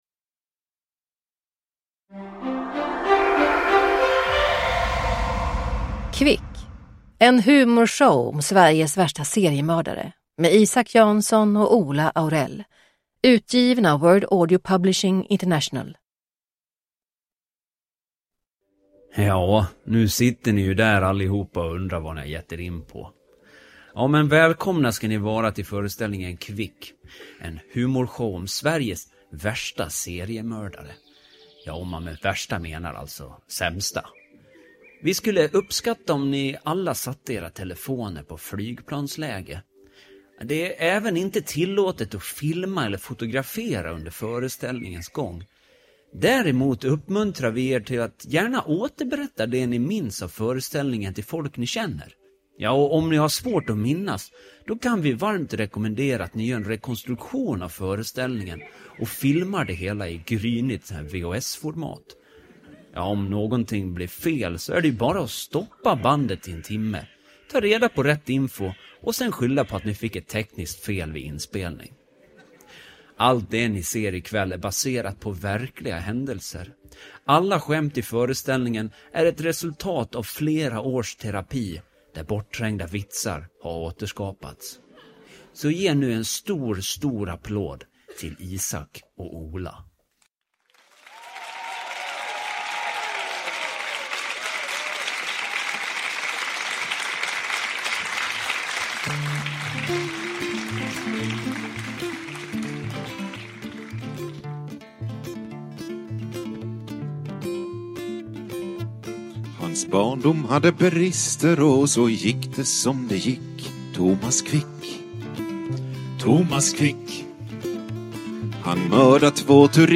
Humor
Ljudbok